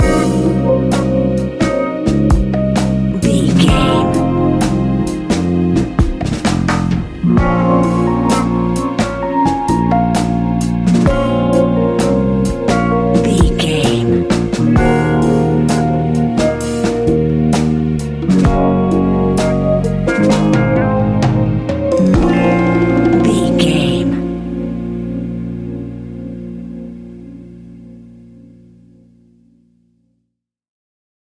Ionian/Major
A♯
hip hop
instrumentals